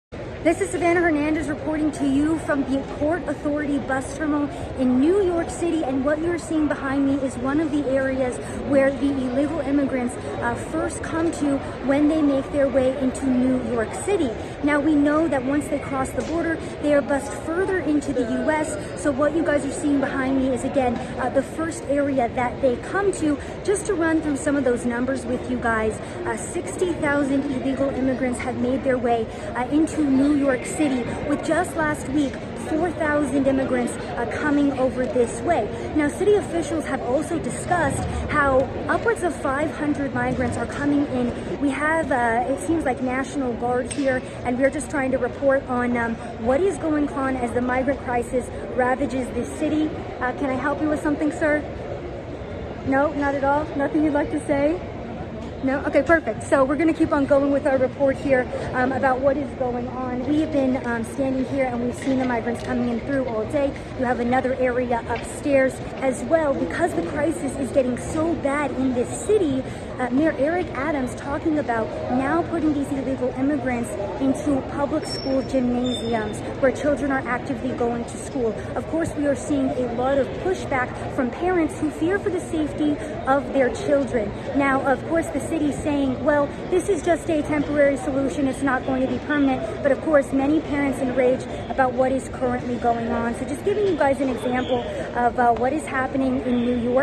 NYC- I’m At The Port Authority Bus Station In NYC, The First Stop Migrants Make When Bussed Into The City: